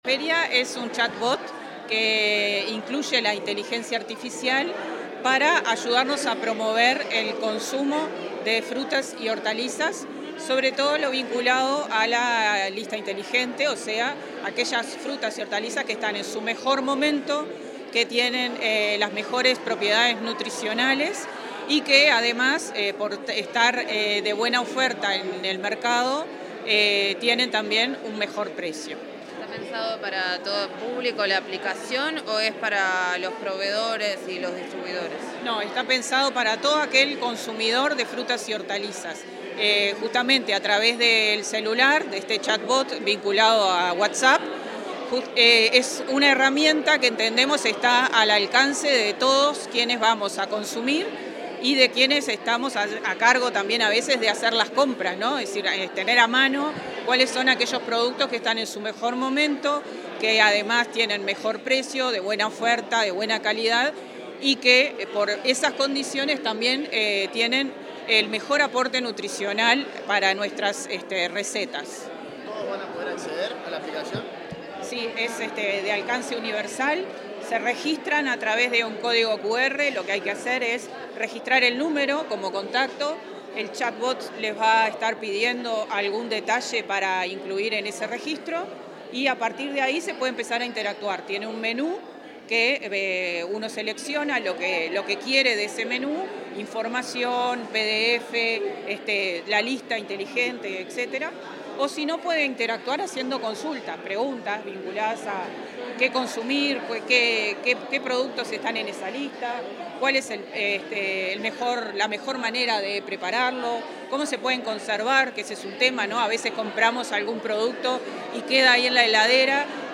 Declaraciones de la directora nacional de la Granja, Laura González